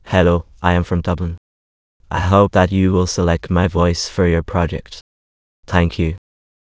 samples/p245.wav · voices/VCTK_European_English_Males at 277d85082b77c4cd9b74d0dcb26c7aeb4277b710